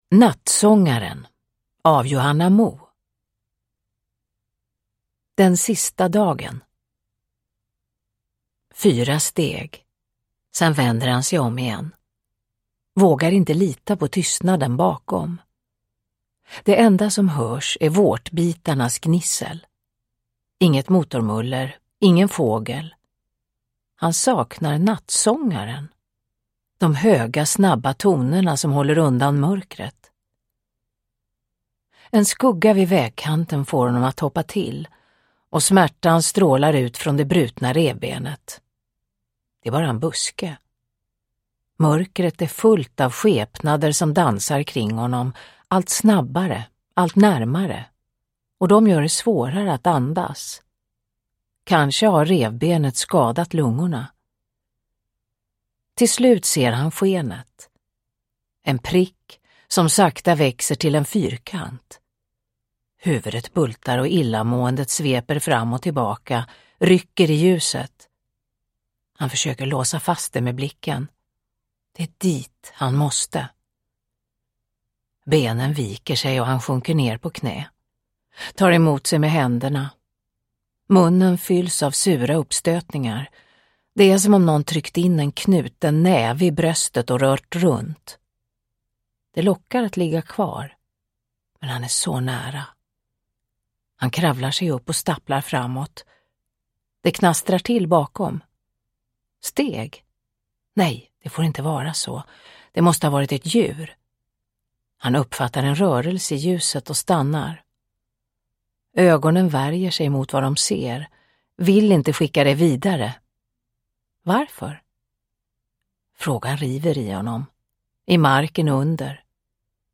Uppläsare: Marie Richardson
Ljudbok